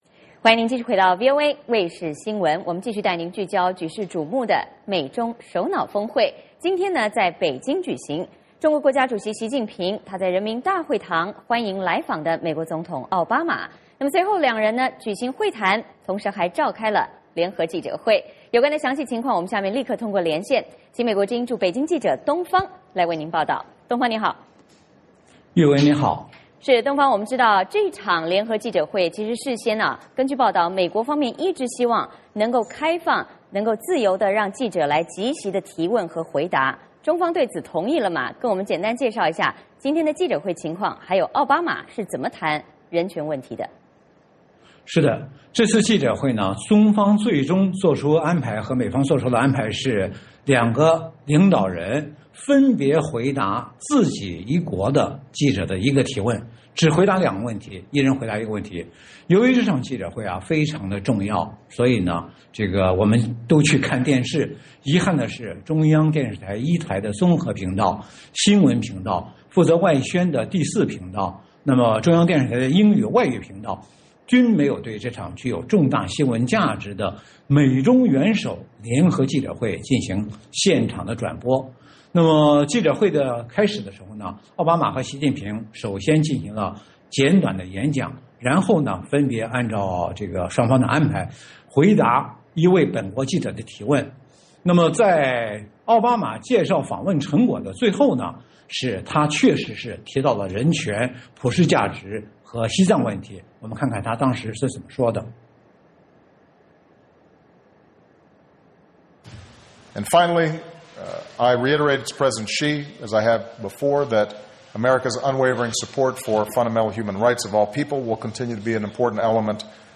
VOA连线：奥巴马会习近平，双方涉及多项议题